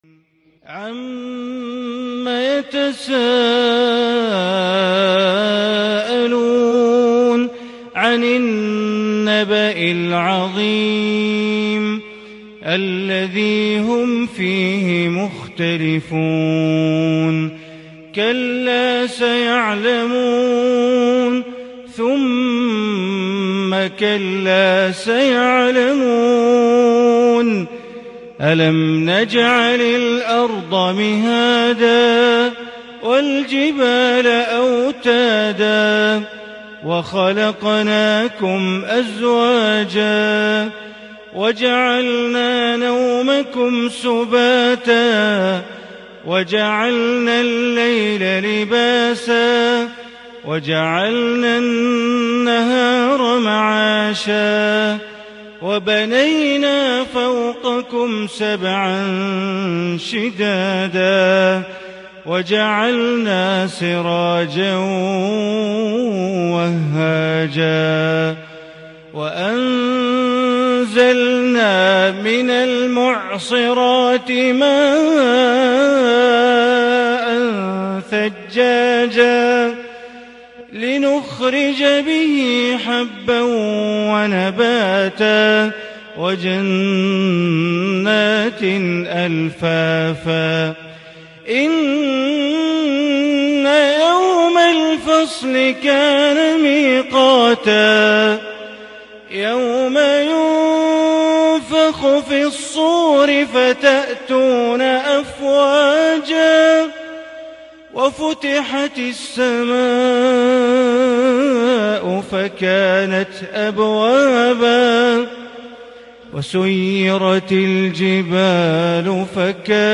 سورة النبأ > مصحف الحرم المكي > المصحف - تلاوات بندر بليلة